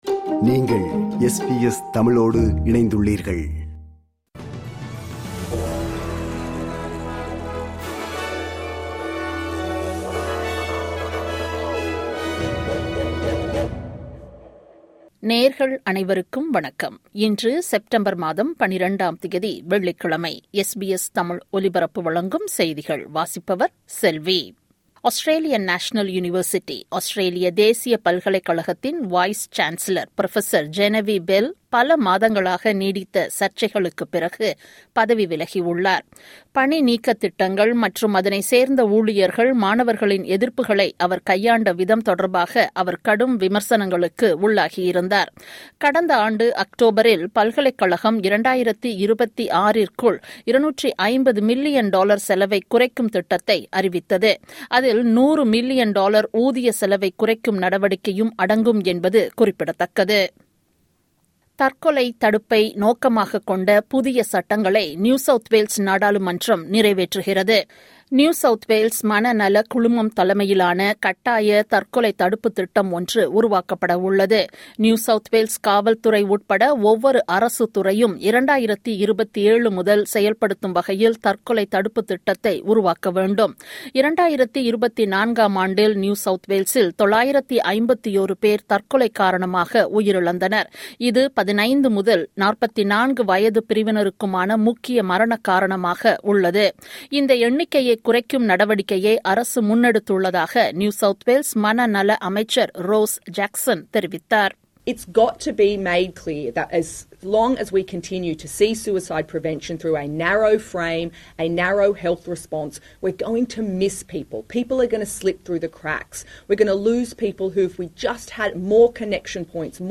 SBS தமிழ் ஒலிபரப்பின் இன்றைய (வெள்ளிக்கிழமை 12/09/2025) செய்திகள்.